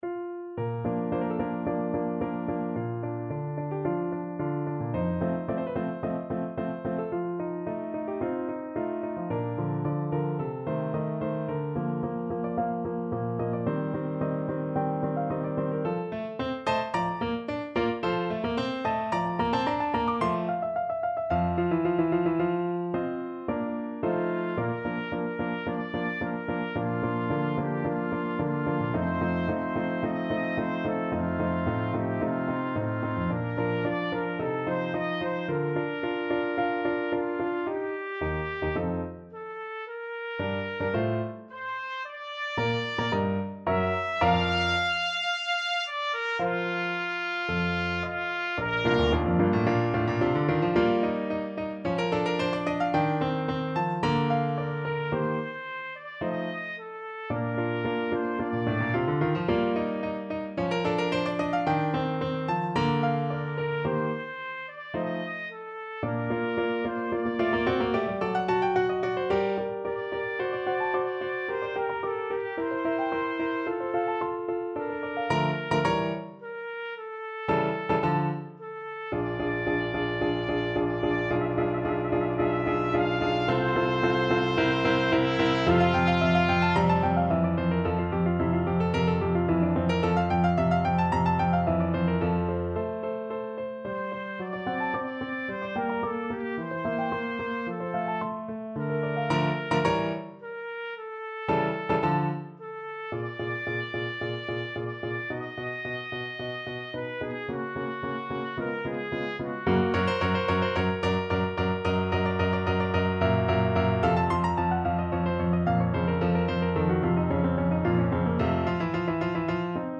Free Sheet music for Trumpet
Trumpet
4/4 (View more 4/4 Music)
Andante =110
Bb major (Sounding Pitch) C major (Trumpet in Bb) (View more Bb major Music for Trumpet )
Classical (View more Classical Trumpet Music)